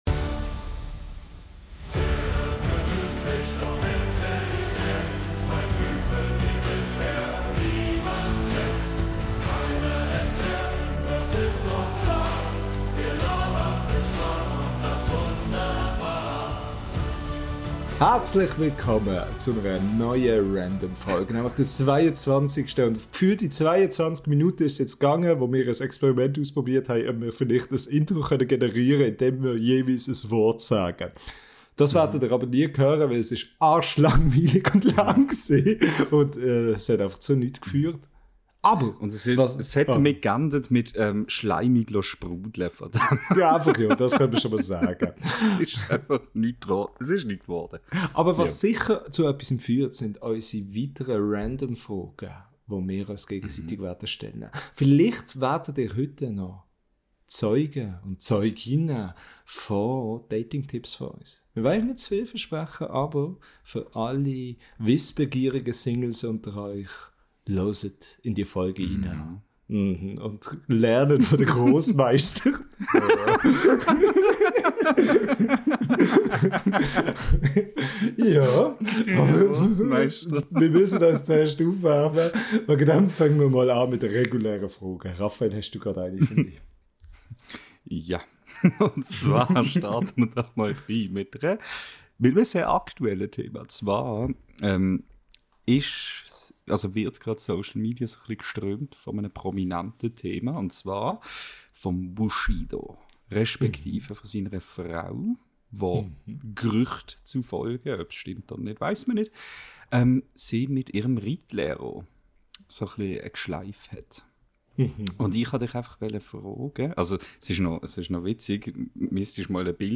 Das ist der Ort, an dem alle ihre Dating Tipps abholen können, gratis, halb fundiert und dafür doppelt so charmant präsentiert von zwei Typen, die vielleicht nicht besonders romantisch sind, aber definitiv unterhaltsam.